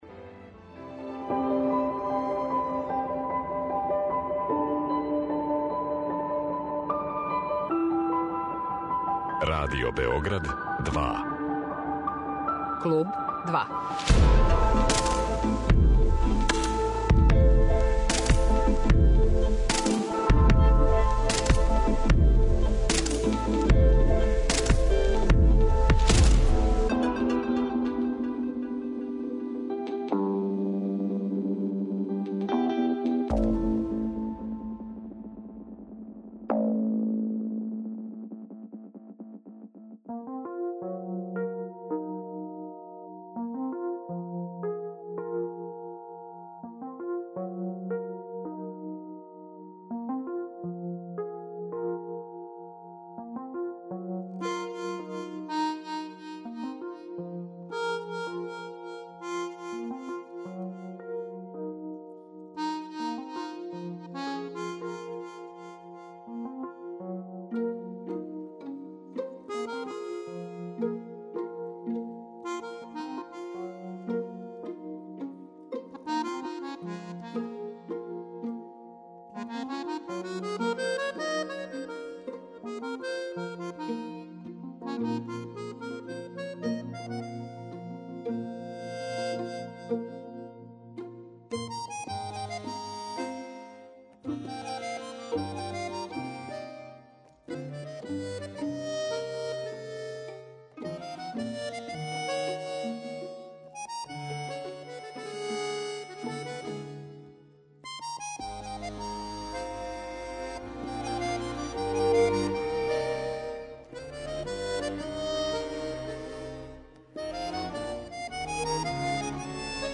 списатељица